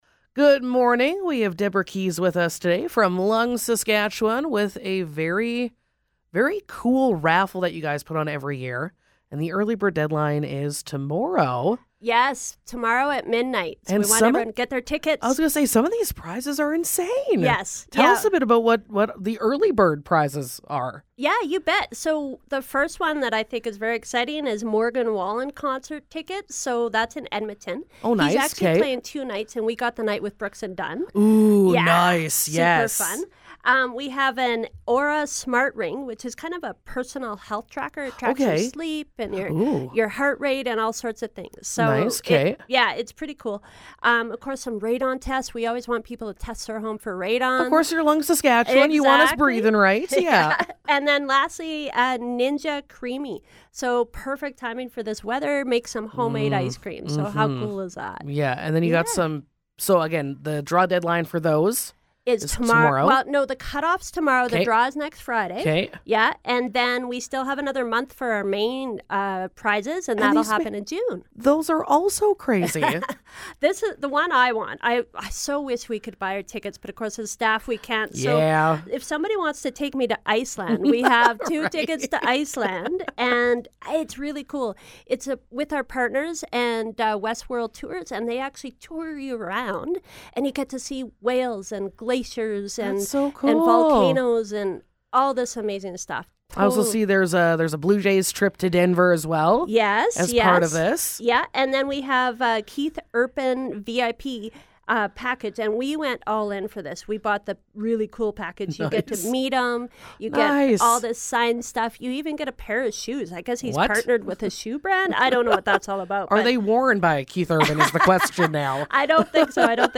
Interview: Lung Sask Breathe Strong Raffle